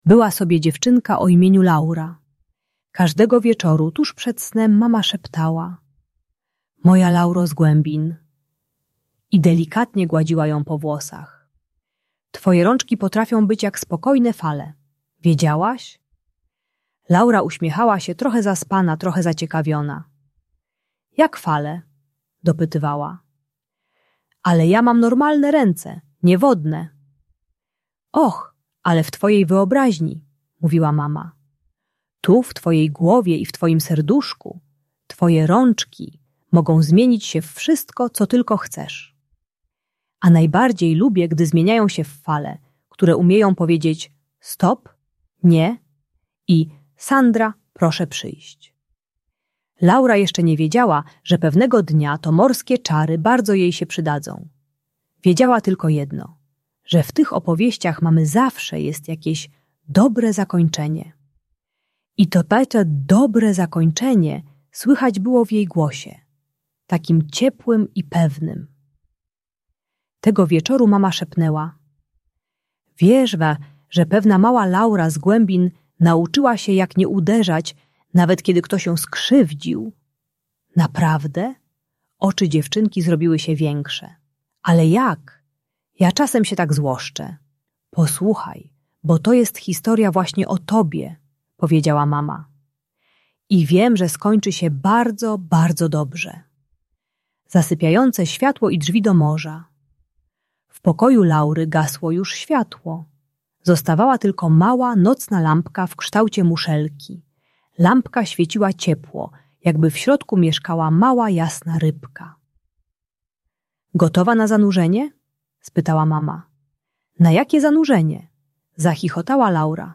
Historia Laury z głębin - Bunt i wybuchy złości | Audiobajka